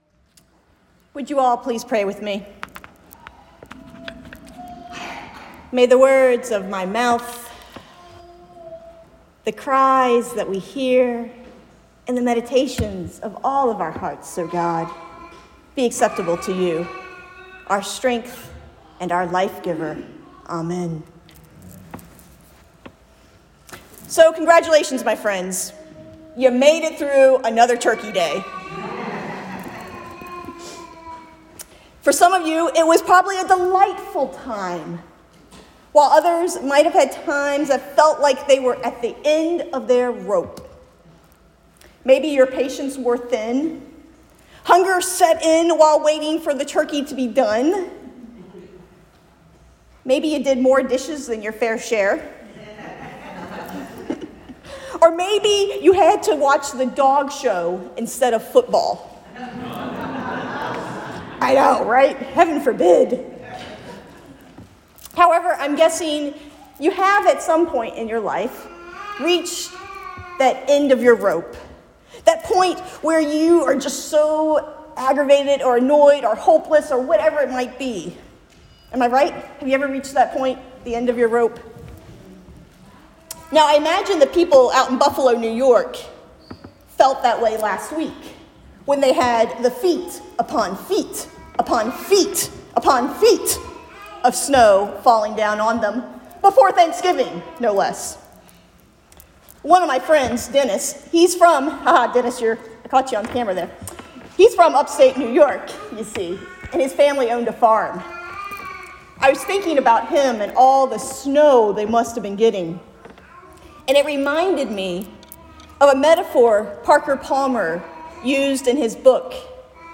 Sermon on Hope for Advent 1